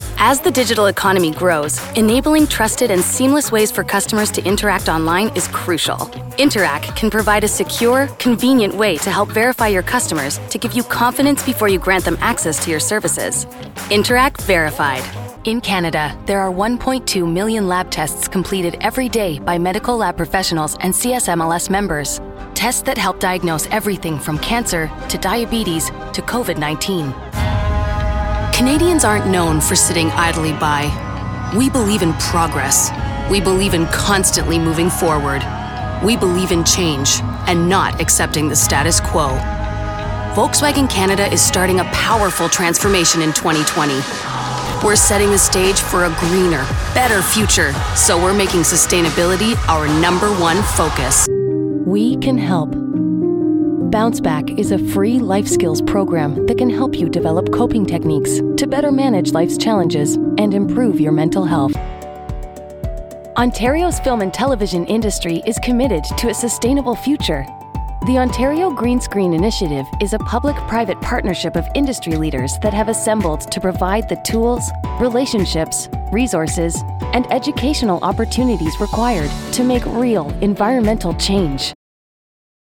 Corporate/Non-Broadcast Narration
English - Midwestern U.S. English
An authentic, conversational, cool voice that's not announcer-y. Unless you want that.